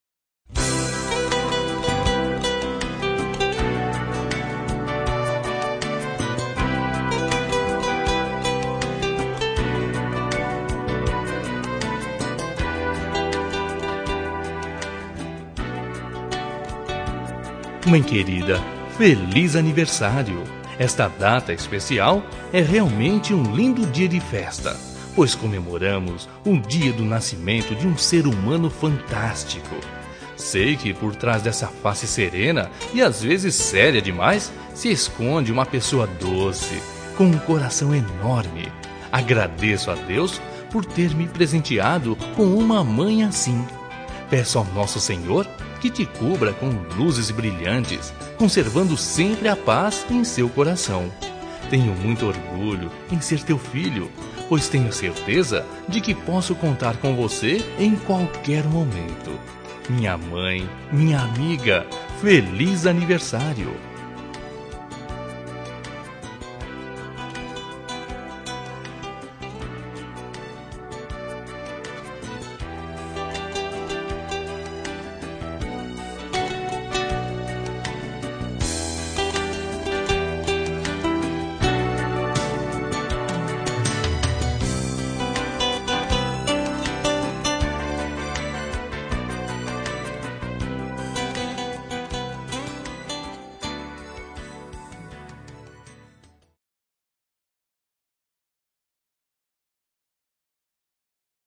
Telemensagem de Aniversário de Mãe – Voz Masculina – Cód: 1427 – Linda